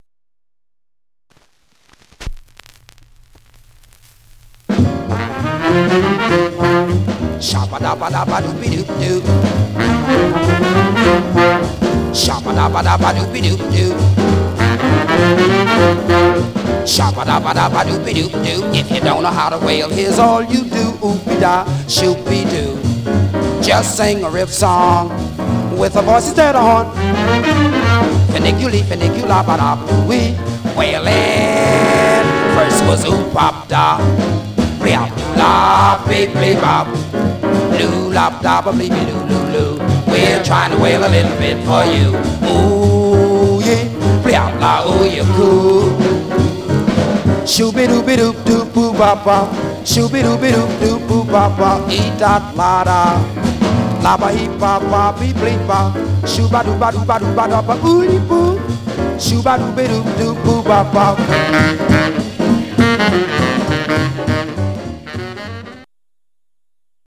Mono
Jazz